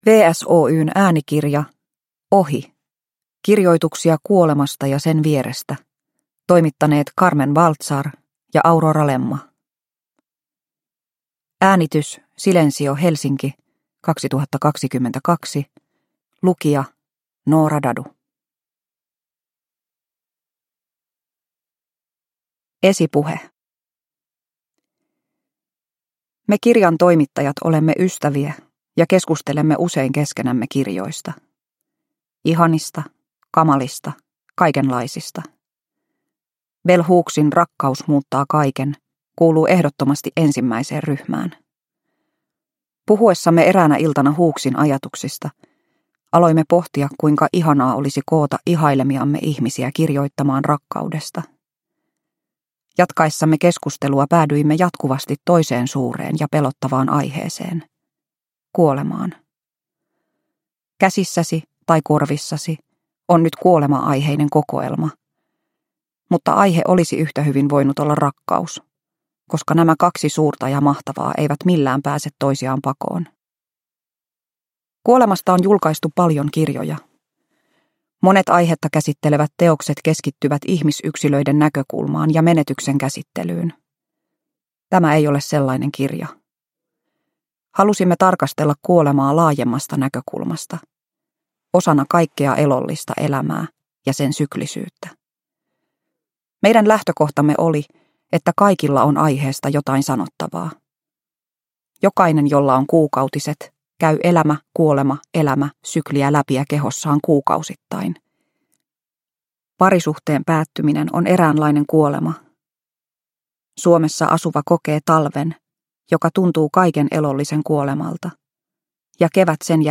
Ohi - kirjoituksia kuolemasta ja sen vierestä – Ljudbok – Laddas ner